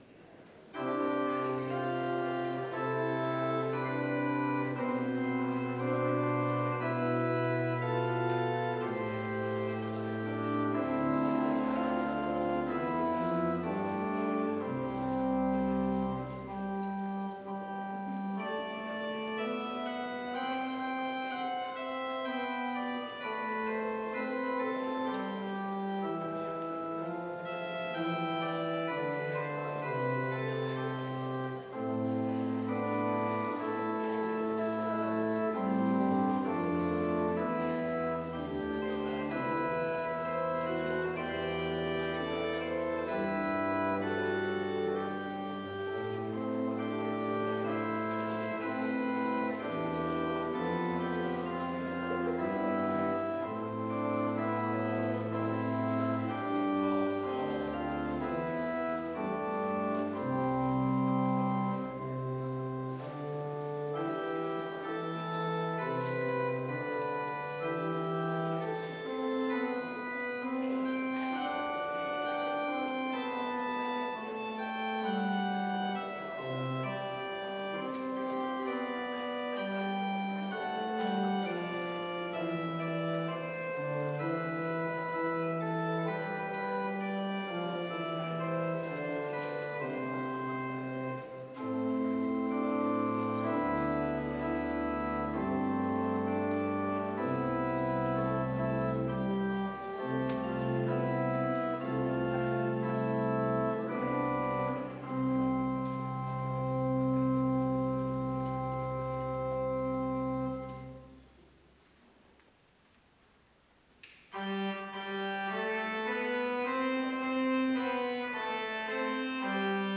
Vespers worship service in BLC's Trinity Chapel
Complete service audio for Vespers - Wednesday, October 22, 2025